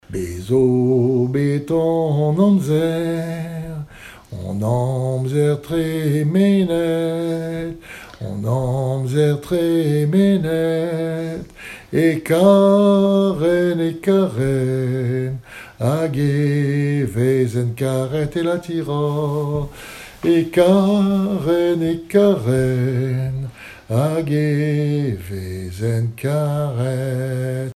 Genre strophique
Cantiques et témoignages en breton
Pièce musicale inédite